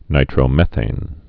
(nītrō-mĕthān)